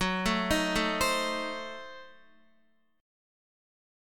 Gbdim7 chord